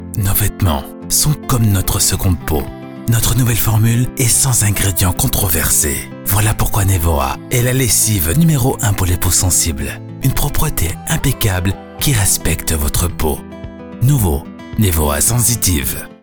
Filmtrailer
Micro SE Electronics und Beats Mixr